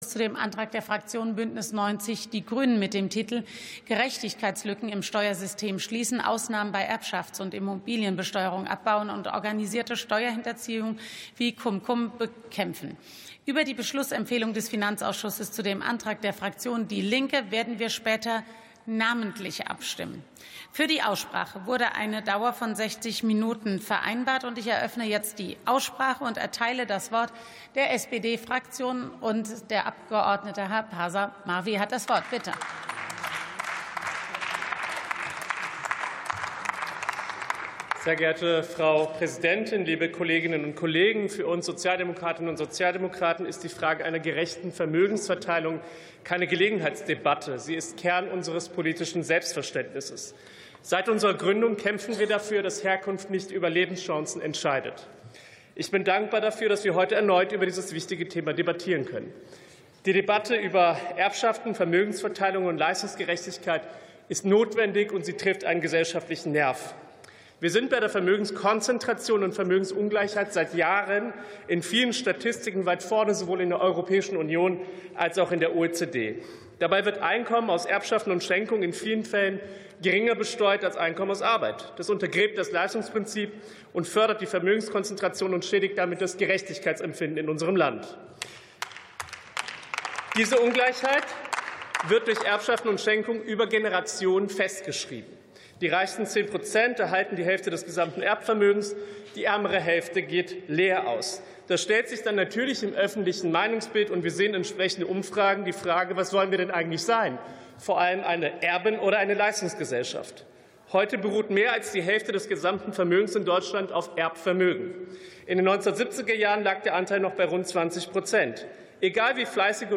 51. Sitzung vom 19.12.2025. TOP 30, ZP 5: Erbschaftsteuer ~ Plenarsitzungen